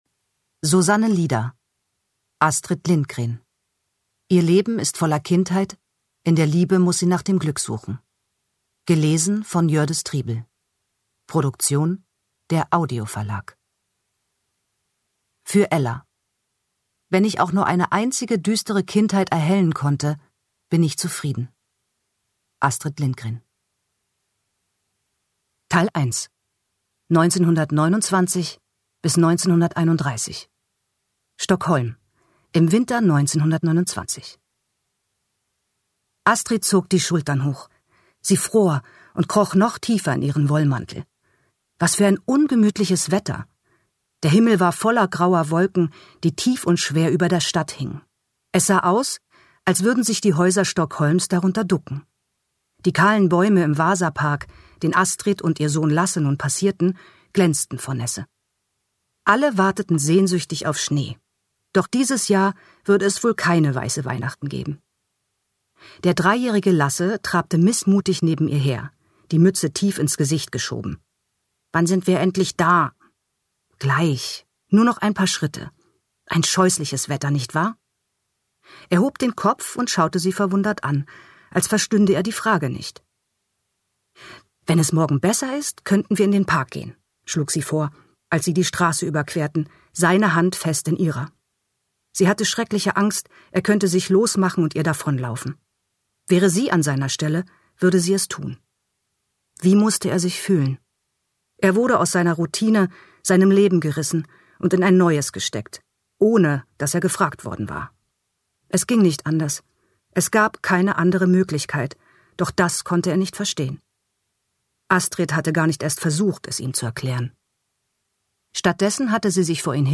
Jördis Triebel (Sprecher)
Ungekürzte Lesung mit Jördis Triebel